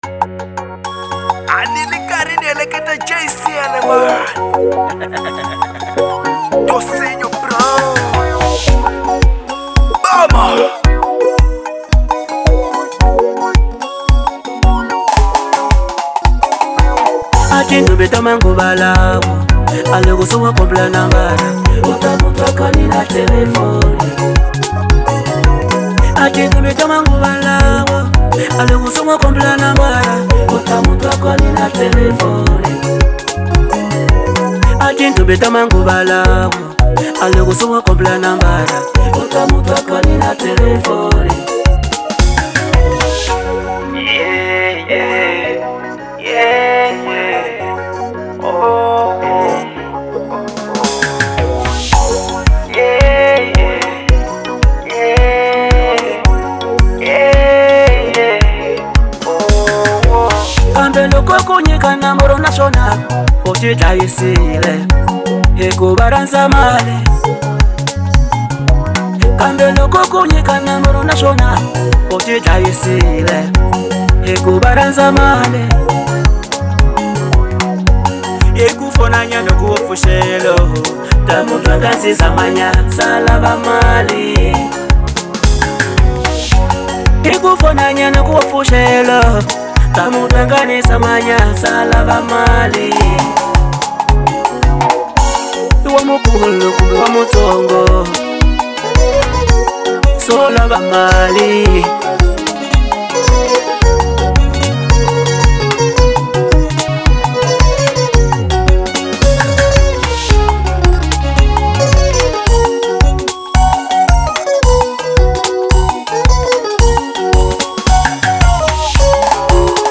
| Afro Classic